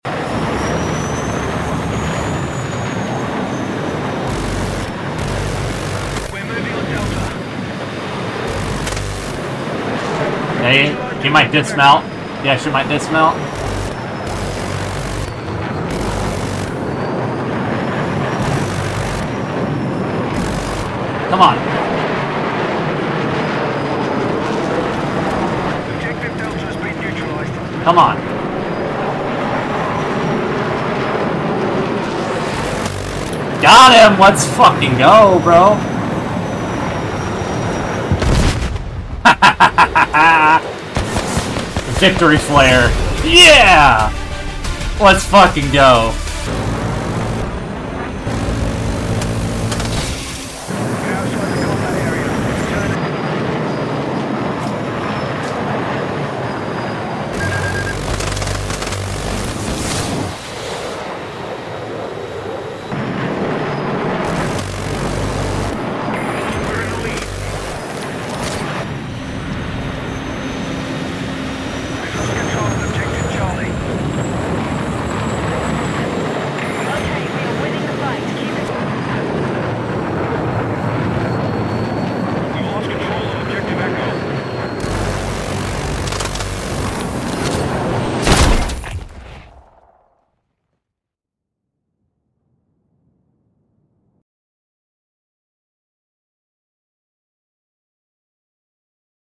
Battlefield 6 Beta Fighter Jets